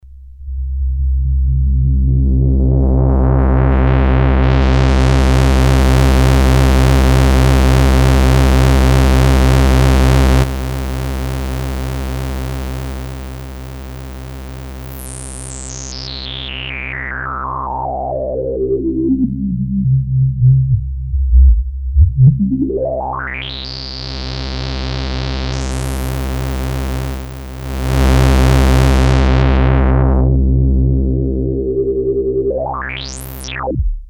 Alpha juno programmer
XP: lowpass and resonance
XP-lowpass.mp3